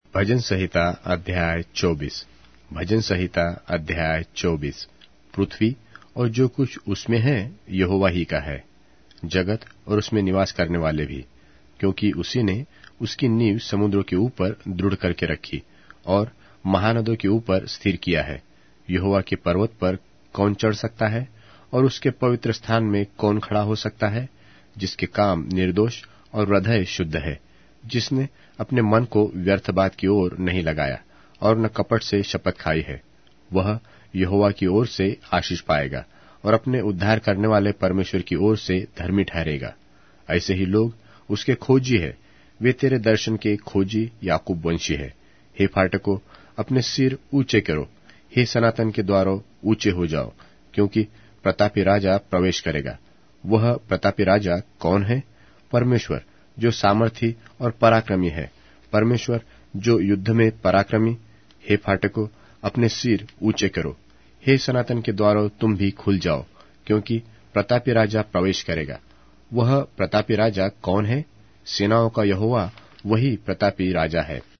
Hindi Audio Bible - Psalms 80 in Tov bible version